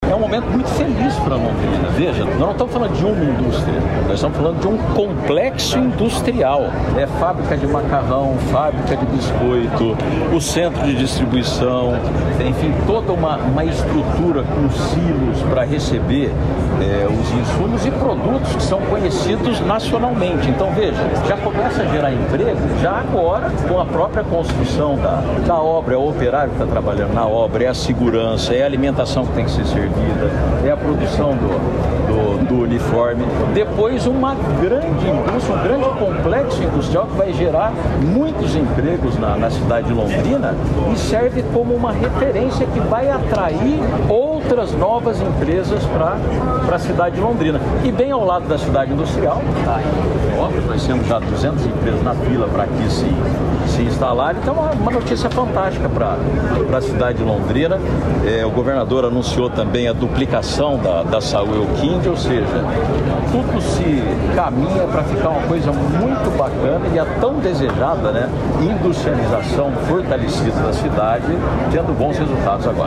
Sonora do prefeito de Londrina, Marcelo Belinati, sobre o novo empreendimento do grupo J.Macêdo na cidade